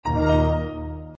high_low_chord.mp3